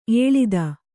♪ ēḷida